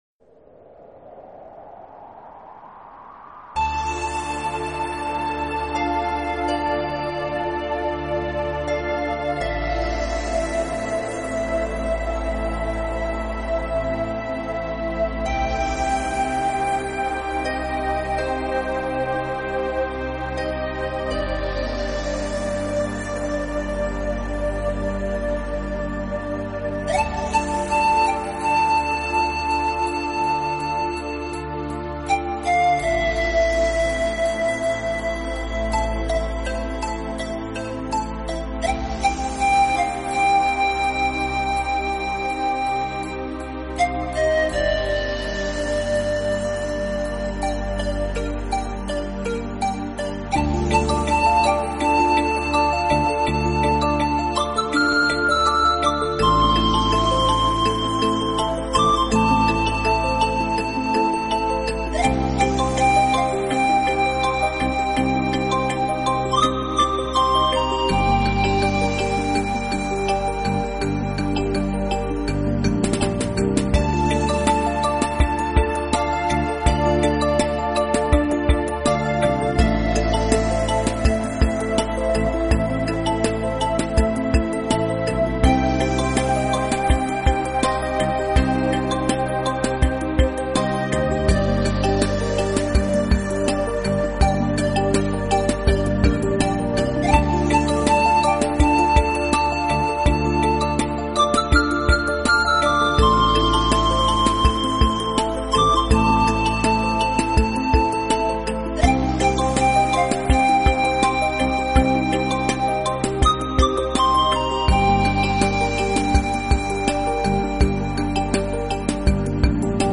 音质而风靡全国，独具一格的空灵乐风，让聆听者随之倾倒。
感受到难能可贵的恬静时光。